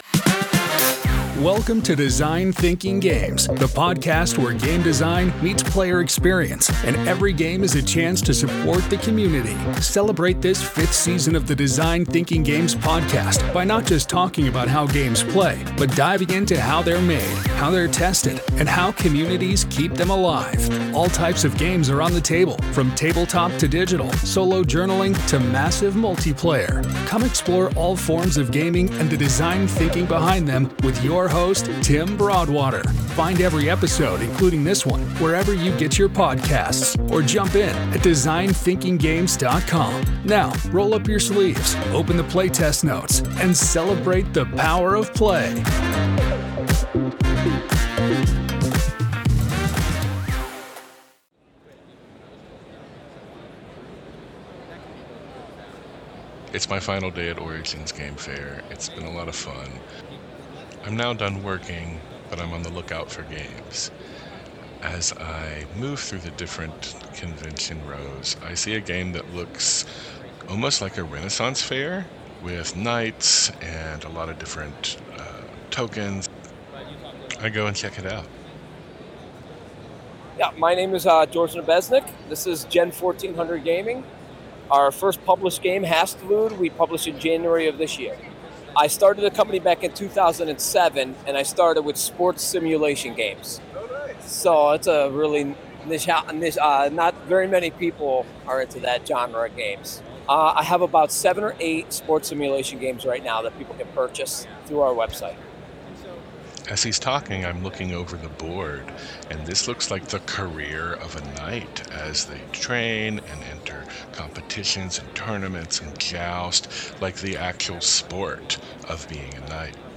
Listen now (24 min) | Origins Game Fair concludes with the games Hasiltude and Rainbow Quest, as well as the charity Extra Life.